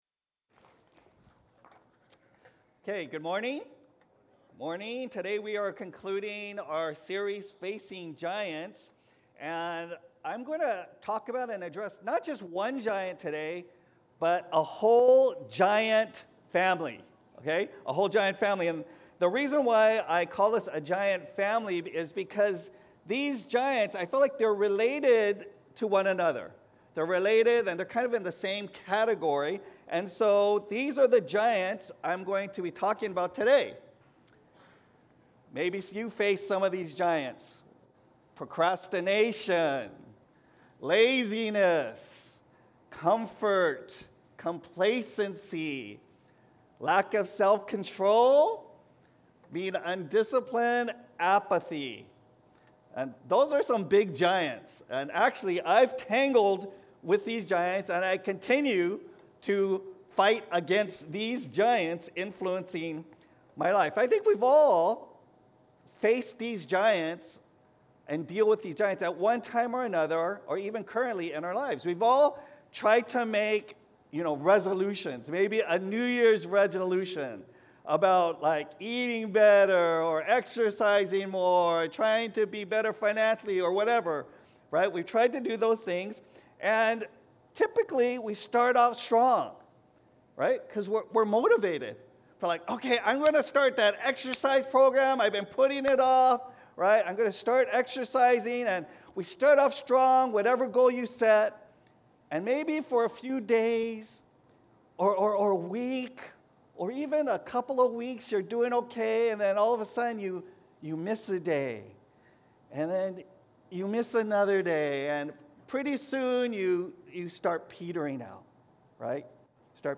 Sermons | Catalyst Christian Community